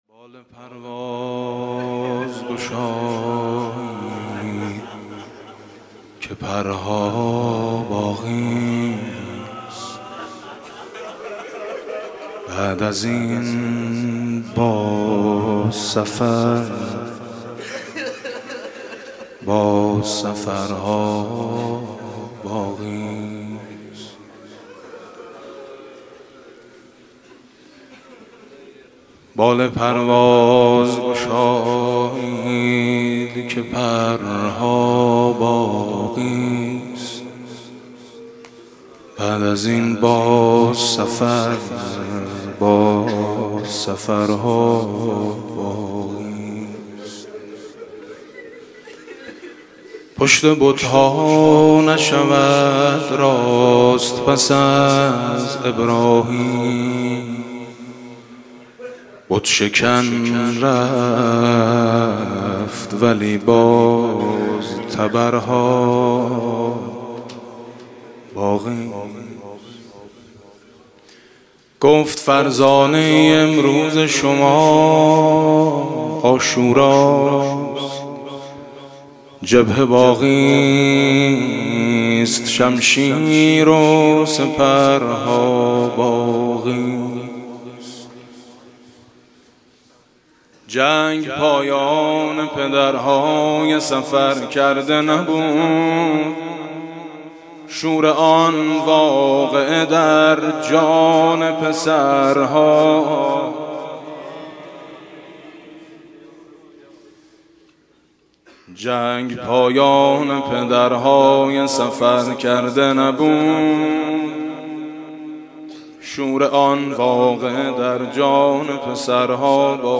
به گزارش خبرنگار فرهنگی خبرگزاری تسنیم، بزرگداشت حماسه غرورآفرین 9 دی‌ماه، شب گذشته هشتم دی ماه در هیئت میثاق با شهدای دانشگاه امام صادق برگزار شد. در این مراسم میثم مطیعی با خواندن اشعاری درمورد حماسه عاشورایی نهم دی، به مداحی پرداخت.